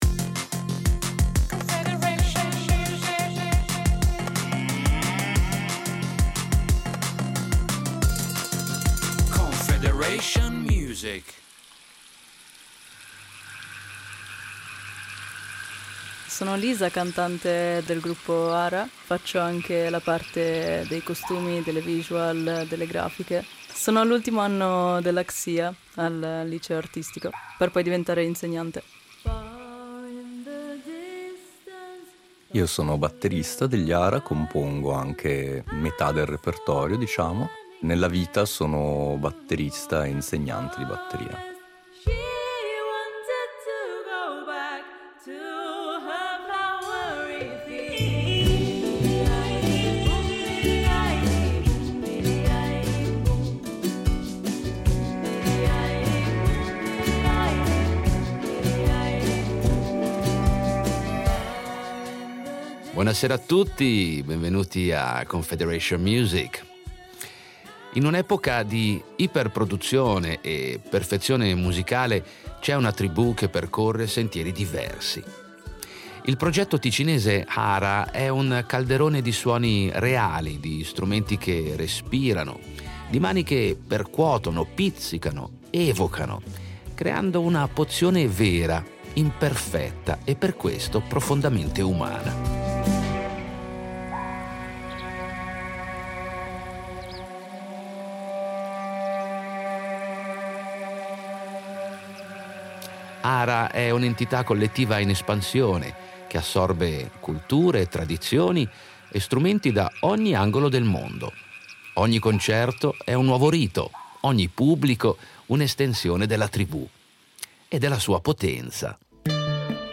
Musica folk
Il progetto ticinese Haara è un calderone di suoni reali, di strumenti che respirano, di mani che percuotono, pizzicano ed evocano creando una pozione “vera” e imperfetta e (proprio per questo motivo) profondamente umana.